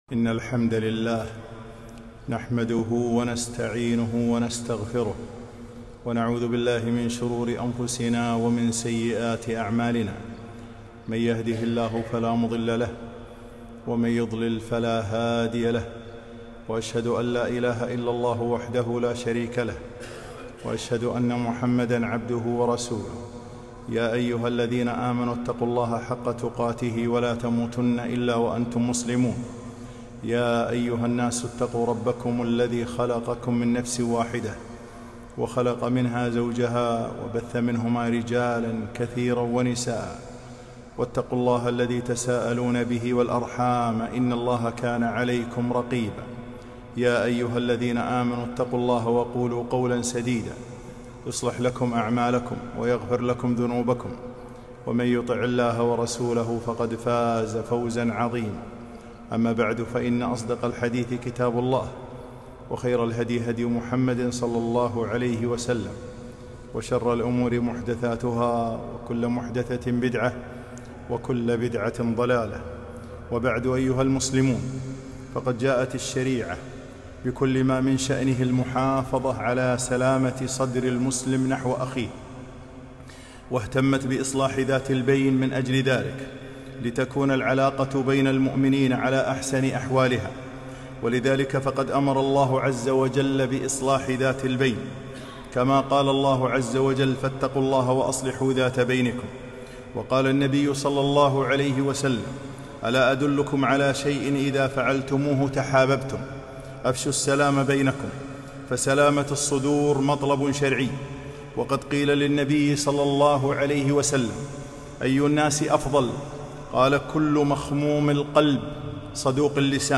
خطبة - سلامة الصدر